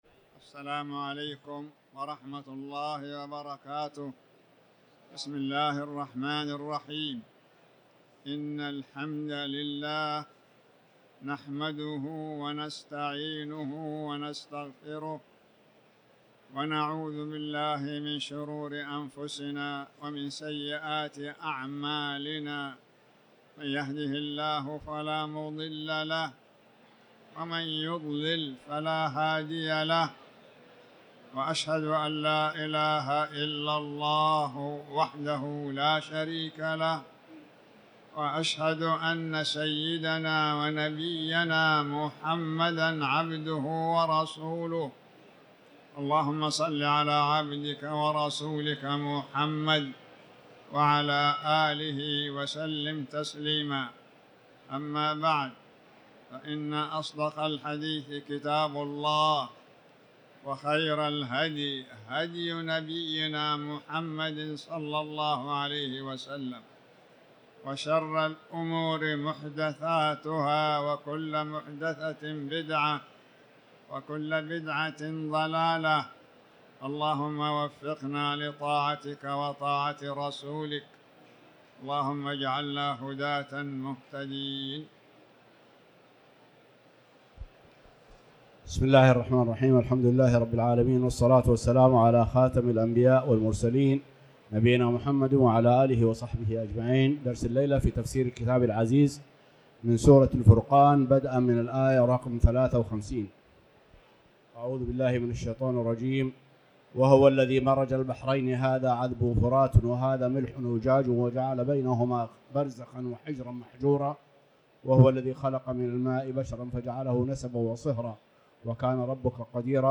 تاريخ النشر ١٧ شعبان ١٤٤٠ هـ المكان: المسجد الحرام الشيخ